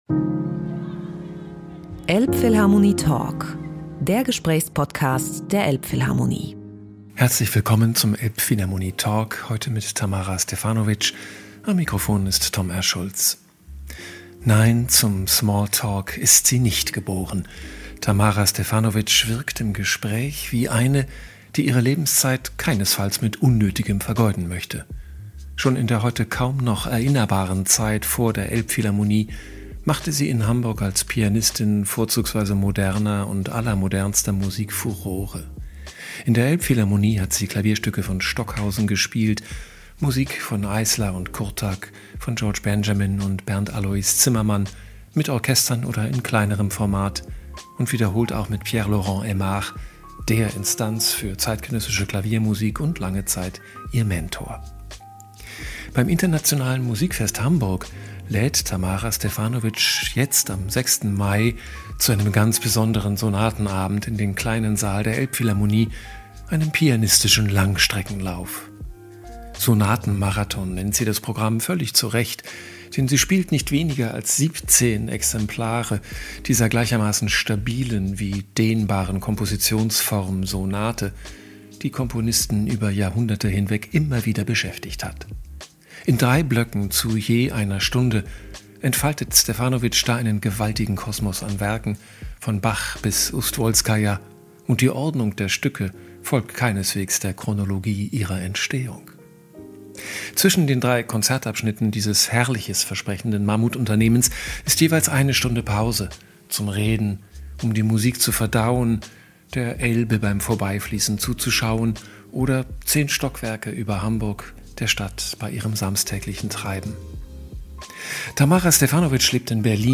Außerdem sinniert sie über den heutigen Interpret:innenkult, über Flow-Momente beim Improvisieren zu viert auf der Bühne, musikalische Einflüsse aus ihrer Kindheit und die kleinen Wendepunkte in ihrer Karriere. Sie erzählt, warum sie zehn Jahre lang kein Klavier gespielt hat und wie sie mit einem Eyeliner während einer Corona-Erkrankung zum Zeichnen gekommen ist. Der Talk fand digital statt.